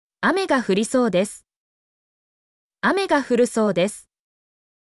mp3-output-ttsfreedotcom-5_Jg8afMlz.mp3